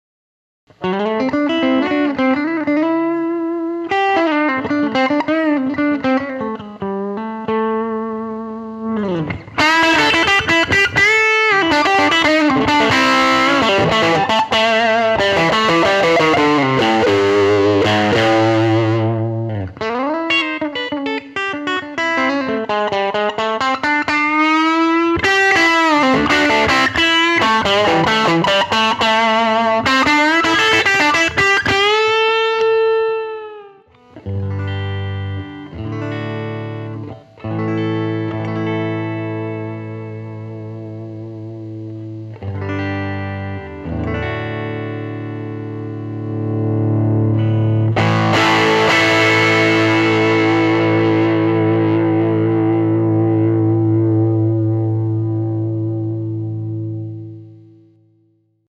It is a single channel amp based on the famous Marshall 18 watt with a few circuit changes that give it some additional features and expand its headroom. This amp goes from chimey clean tones, to smokey blues and tops out with classic rock.
03_Strat_volume_control.mp3